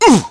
Clause-Vox_Damage.wav